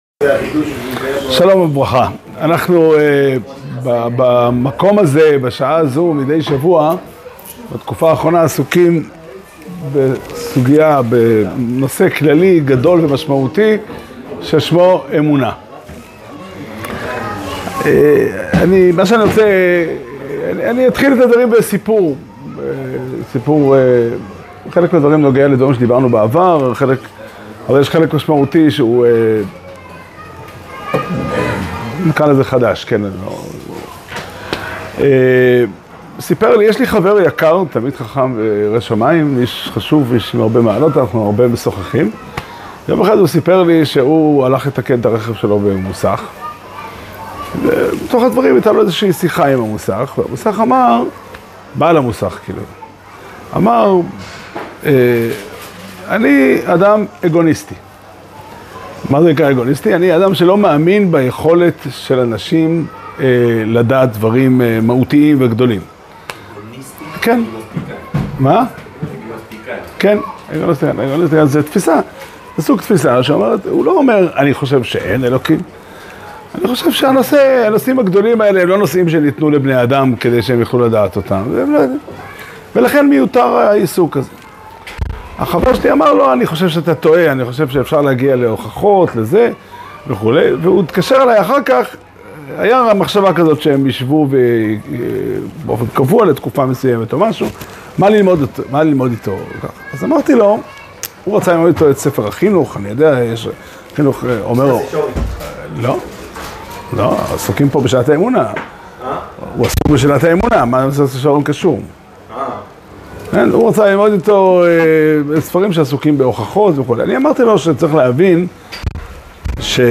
שיעור שנמסר בבית המדרש פתחי עולם בתאריך י"ב חשוון תשפ"ה